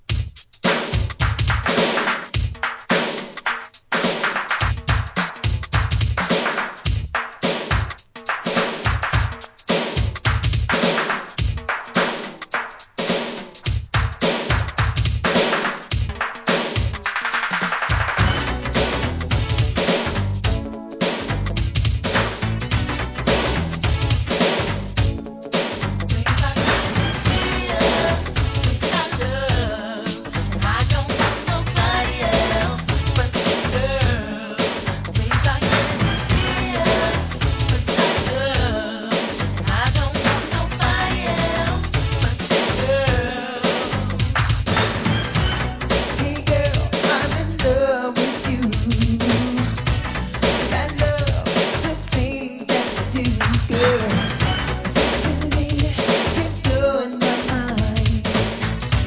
HIP HOP/R&B
NEW JACK SWING!!